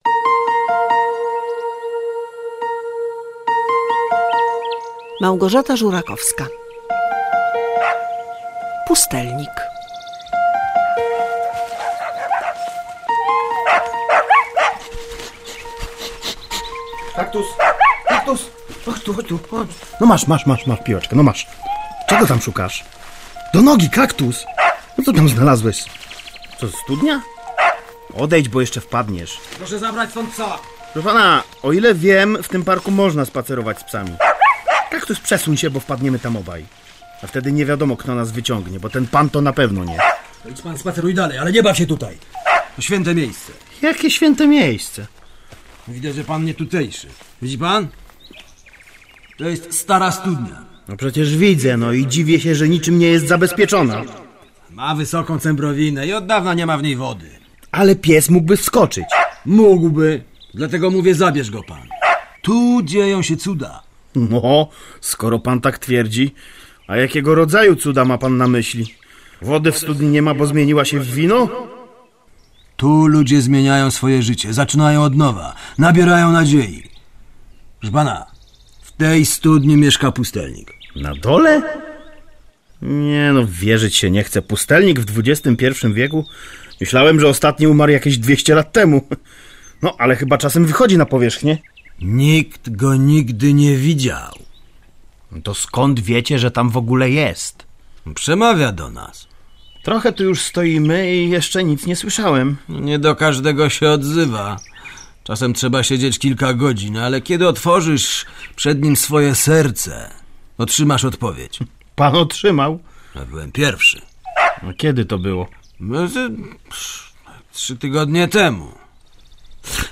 W programie słuchowisko o starej studni, w której żyje pustelnik będący wsparciem dla ludzi w ich życiowych problemach oraz losy Tarzana 30 lat później...